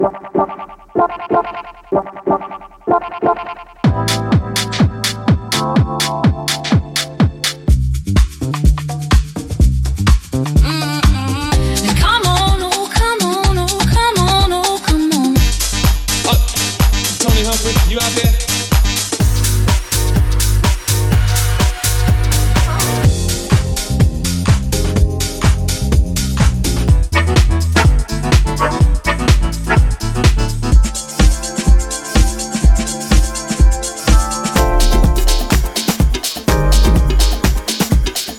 Soulful House/Classic House/Deep House/Neo Soul